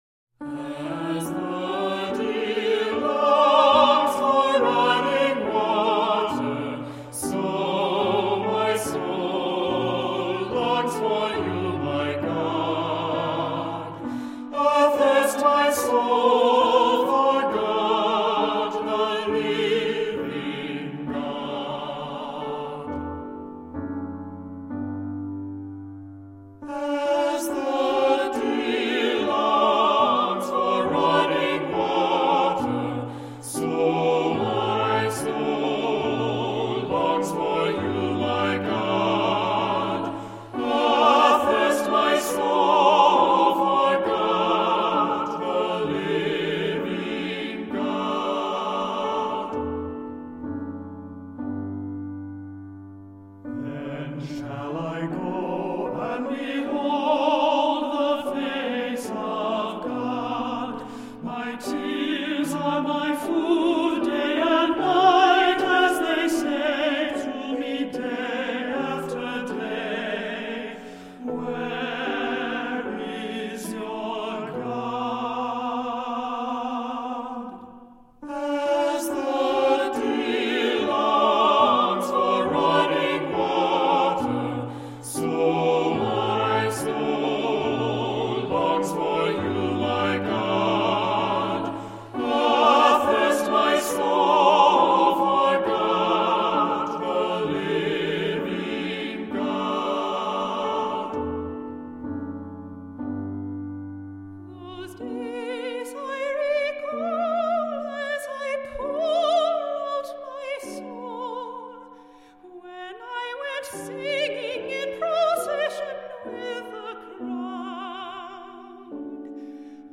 Voicing: Cantor,SATB, assembly